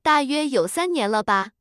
tts_result_3.wav